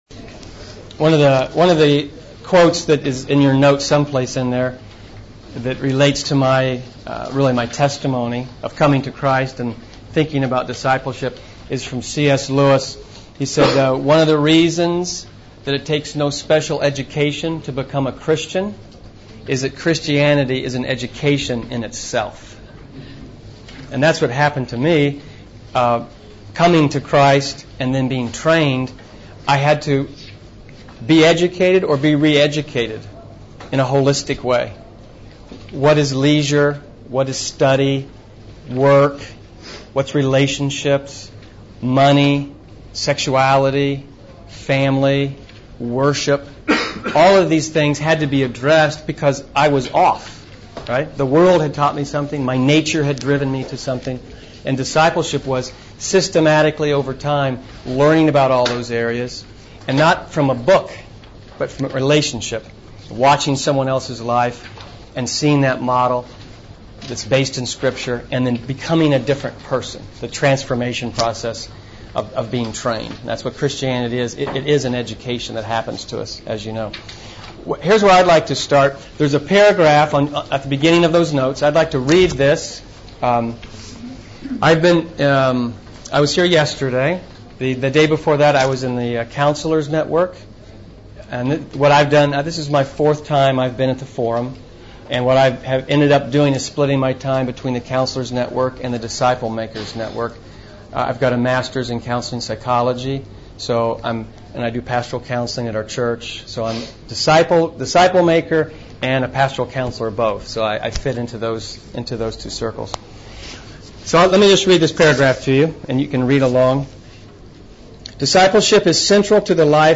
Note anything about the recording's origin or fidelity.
Event: ELF Post-Forum Seminar